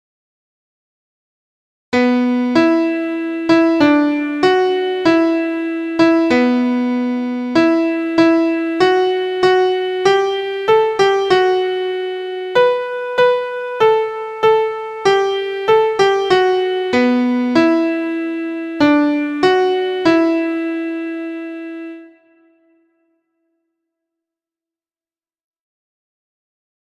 Uncommon intervals in E minor.
• Origin: Traditional
• Key: E minor – pitched in G Major
• Time: 4/4
• Musical Elements: notes: dotted half, half, dotted quarter, quarter, eighth; pickup beat, vocal slur, minor tonality, uncommon intervals